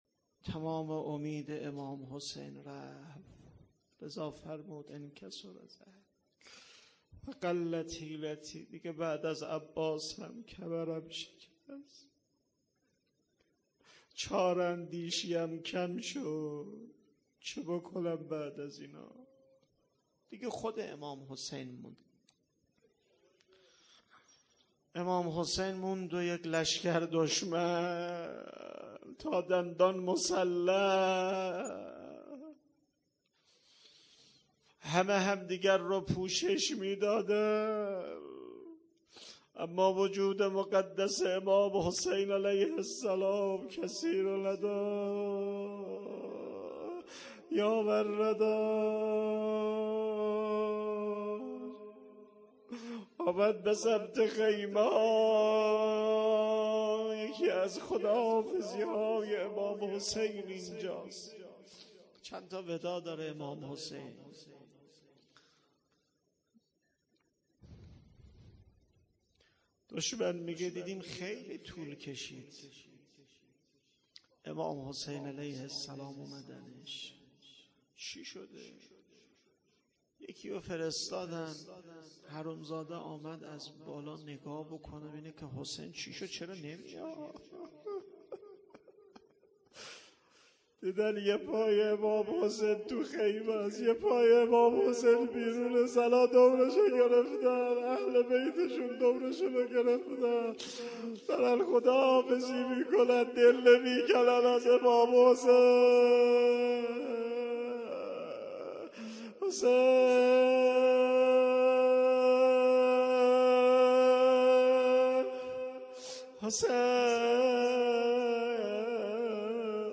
هیئت عقیله بنی هاشم سبزوار